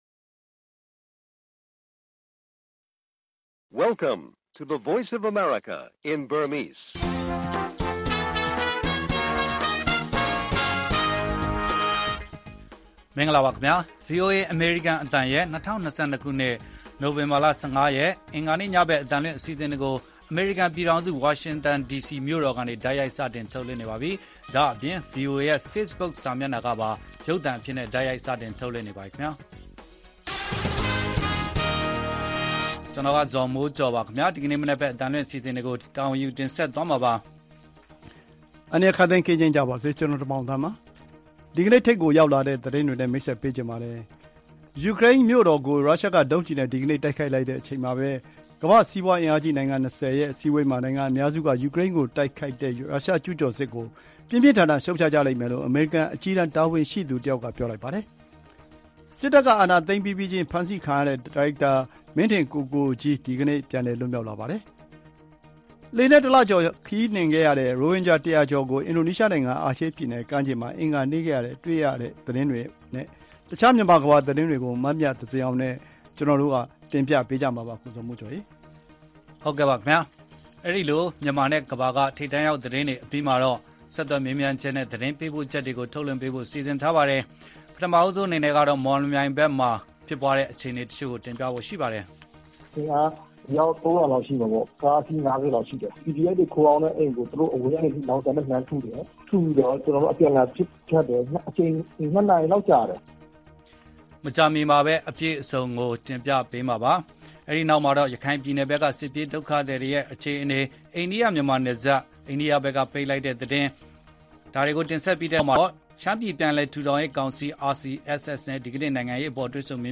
ဗွီအိုအေ တွေ့ဆုံမေးမြန်းချက်